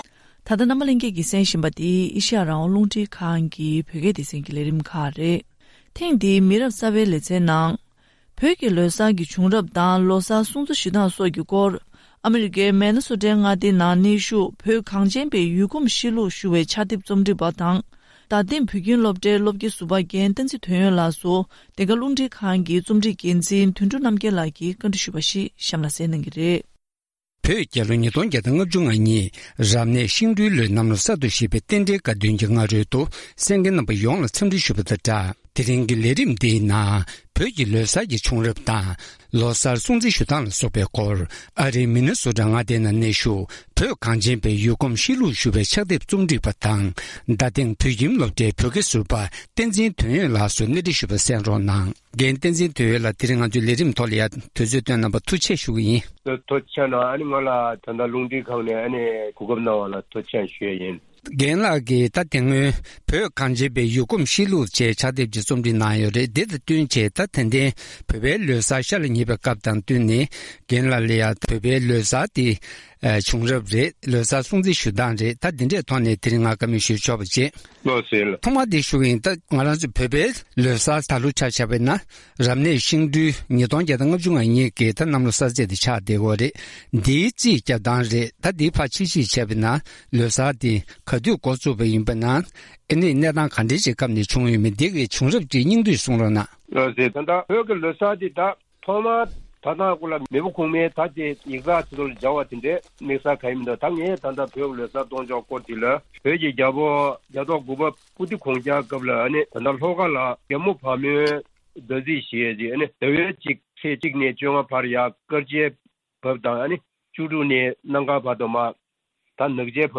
གནས་འདྲི་ཞུས་པ།